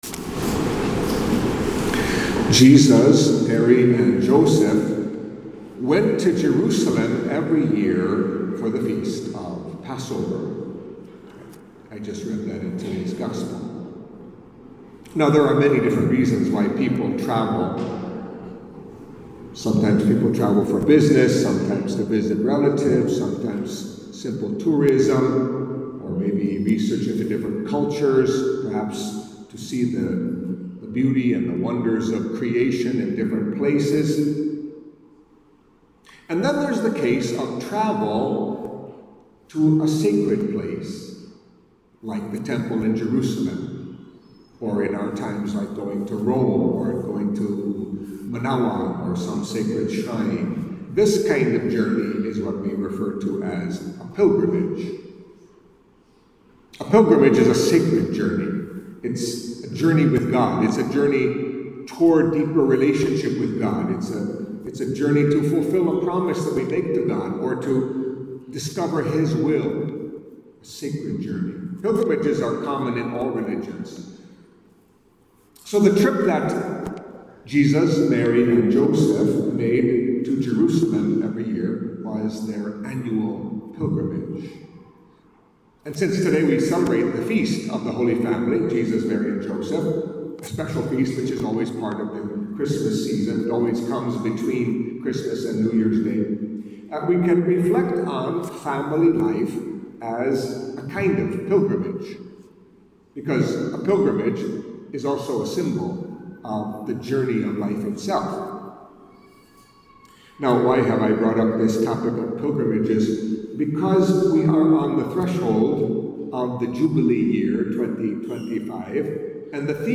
Catholic Mass homily for the Feast of the Holy Family of Jesus, Mary, and Joseph